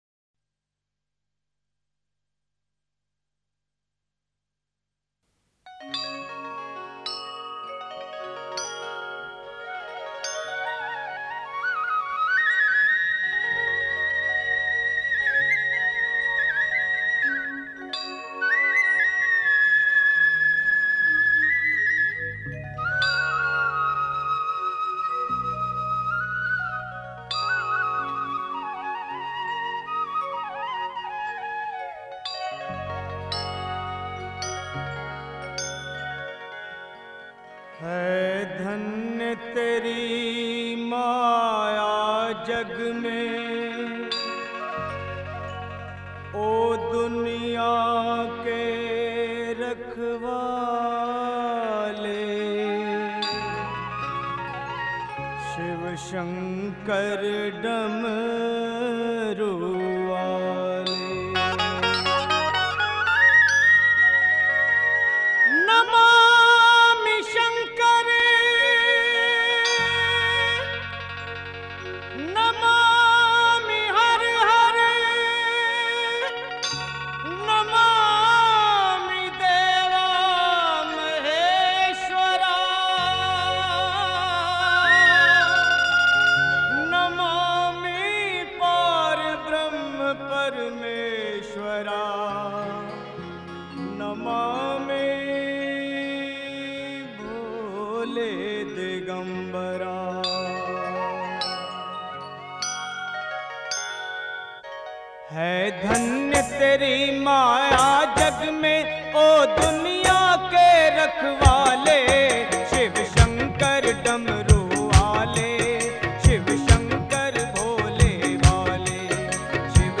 शिव भजन